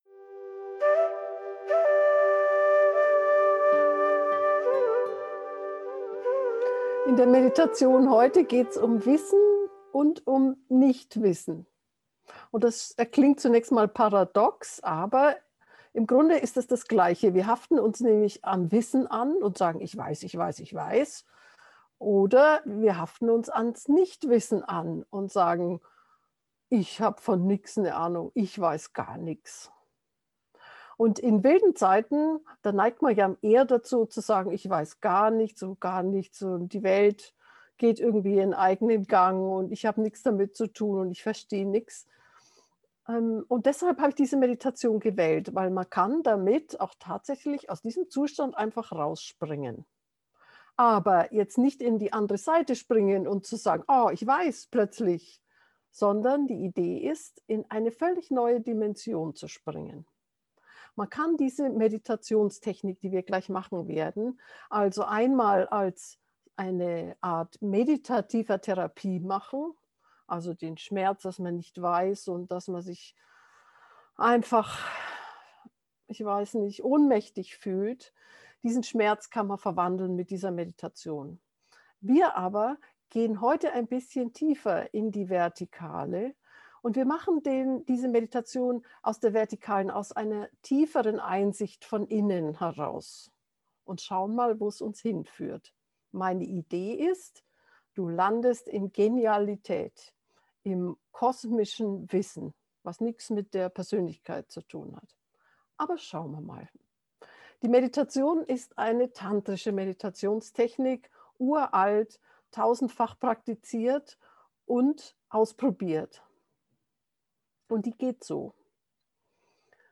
Geführte Meditationen Folge 124: Jenseits von Weisheit und Unwissenheit Play Episode Pause Episode Mute/Unmute Episode Rewind 10 Seconds 1x Fast Forward 10 seconds 00:00 / 18:11 Subscribe Share RSS Feed Share Link Embed
wissen-nichtwissen-meditation.mp3